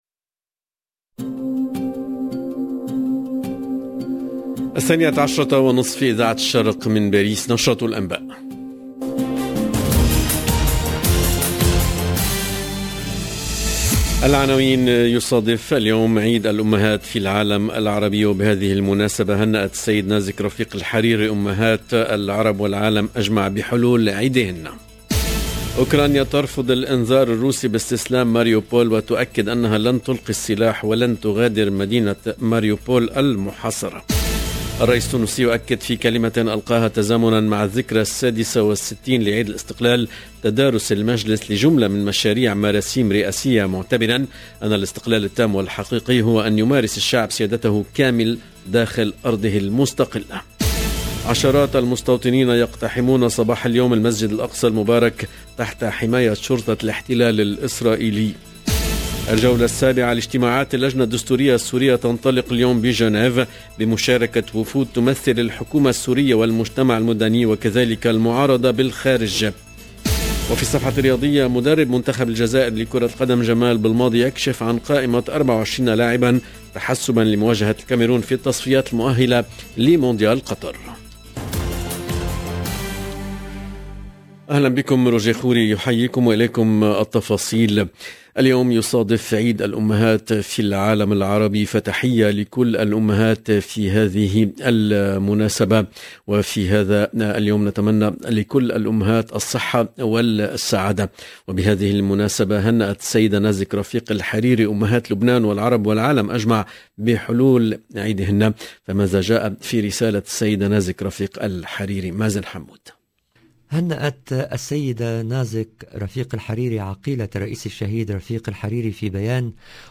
LE JOURNAL EN LANGUE ARABE DE MIDI 30 DU 21/03/22